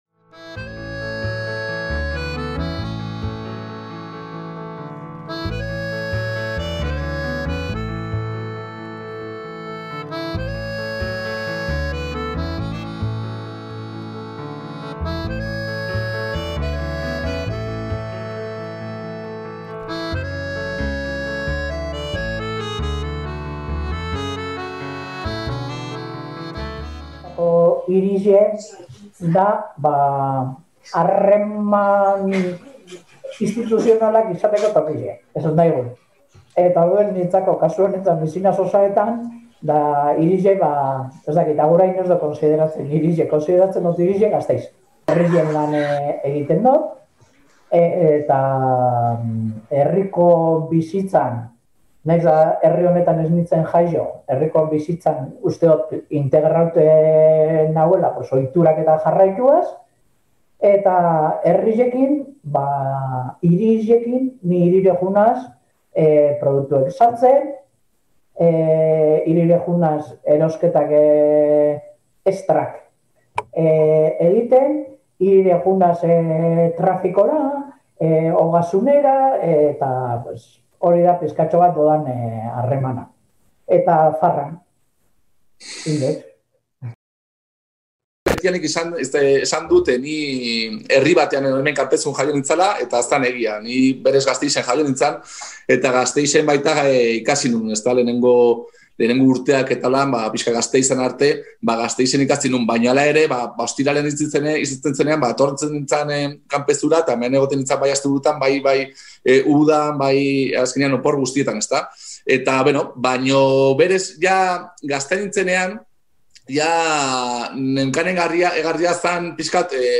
Arabako landa ingurunean bizitzeko hautua egin duten bi pertsona hizketan arituko dira hil honetan.